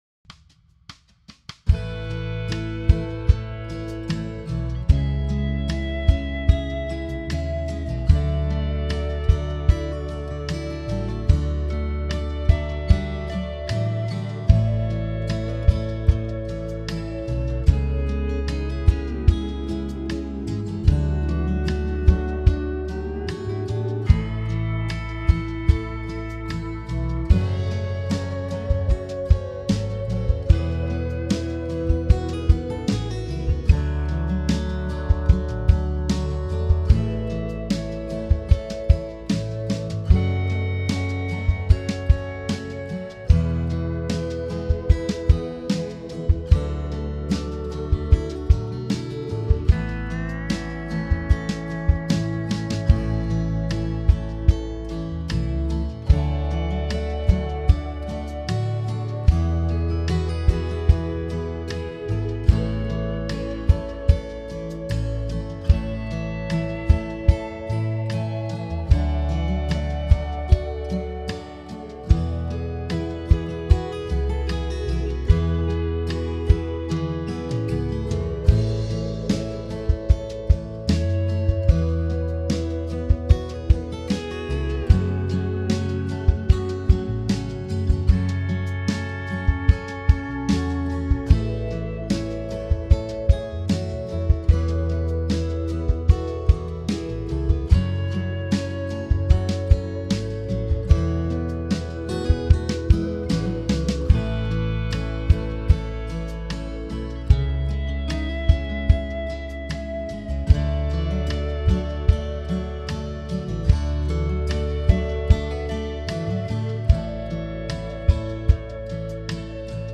Home > Music > Rock > Bright > Smooth > Medium